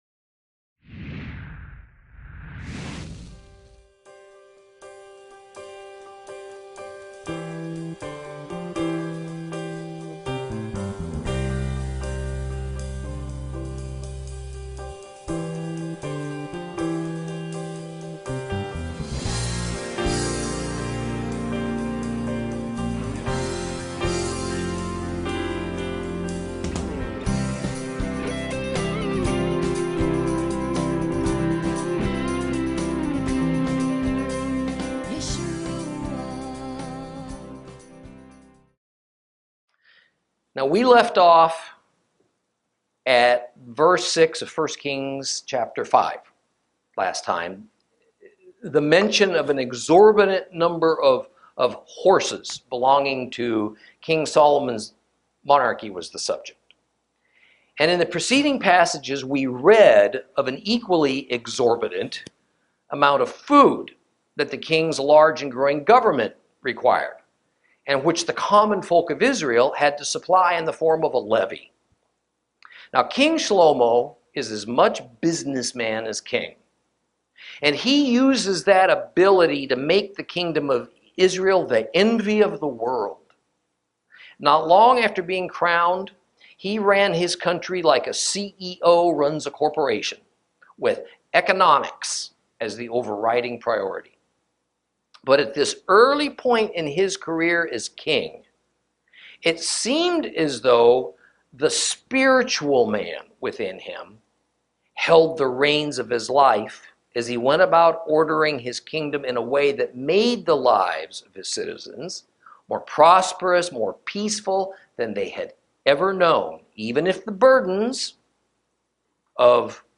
Lesson 9 Ch5 Ch6 - Torah Class